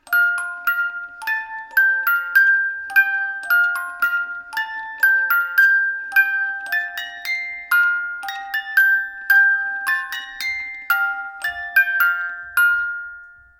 Cutiuţe muzicale prelucrate manual din lemn de esenţă tare